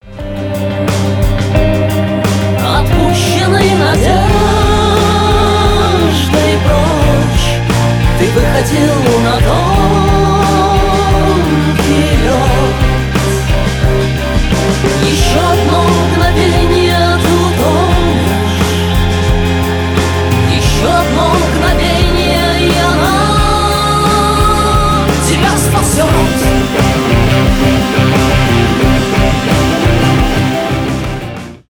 рок , русские , легкий рок , фолк-рок